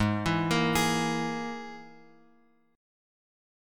G# Suspended 2nd Sharp 5th